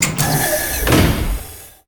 doorclose6.ogg